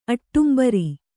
♪ aṭṭumbari